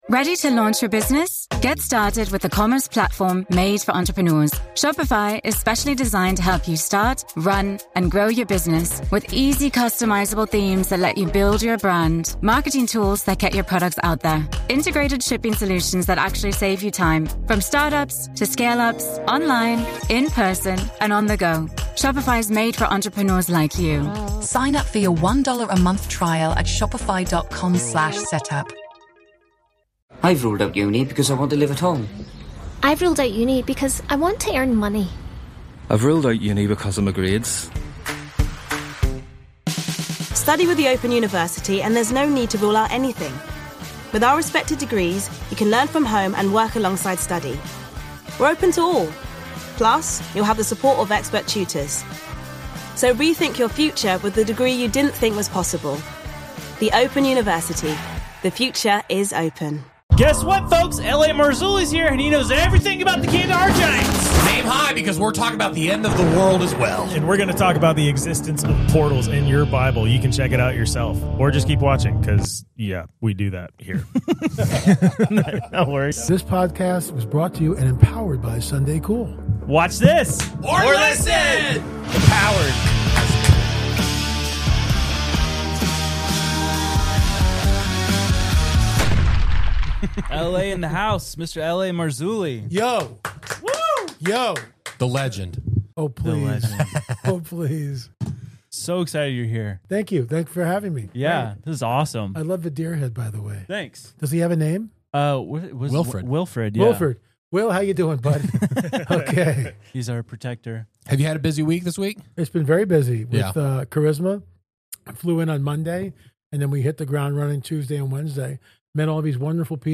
From the legendary Kandahar Giant and its connection to hidden Nephilim, to the possibility of ancient portals opening around the world, and how these events may tie into a coming apocalypse, this conversation pushes deep into the questions few are willing to ask.